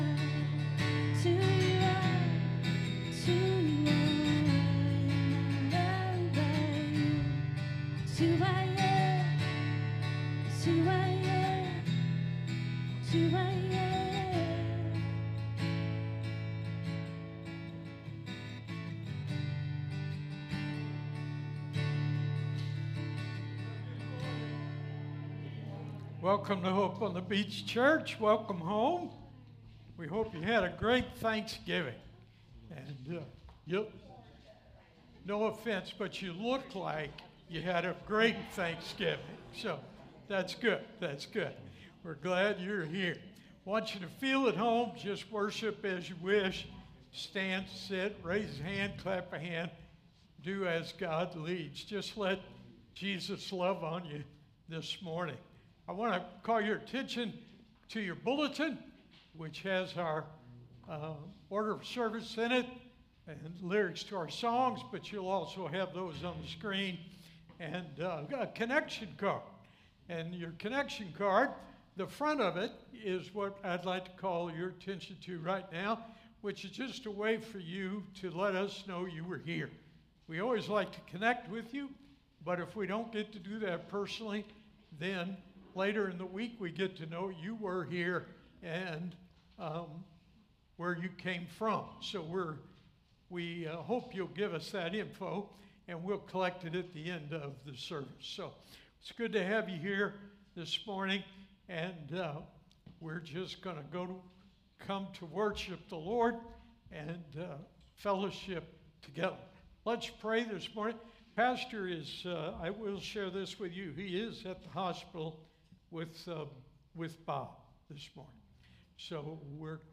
SERMON DESCRIPTION Advent means “coming,” reminding us that even when hope feels deferred, God’s promise in Jesus never fails.